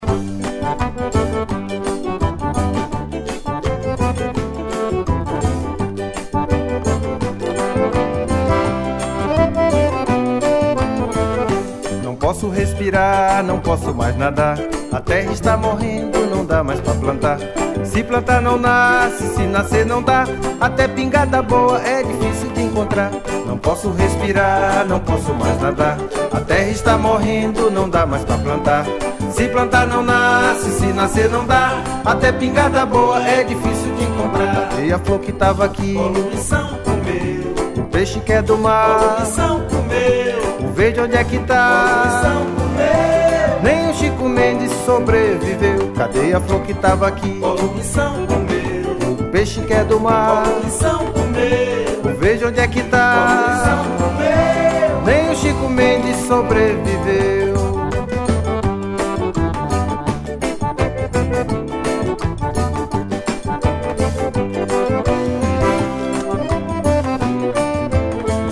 1495   01:08:00   Faixa:     Forró
Acoordeon
Triângulo
Guitarra
Baixo Elétrico 6
Cavaquinho
Bateria
Zabumba, Pandeiro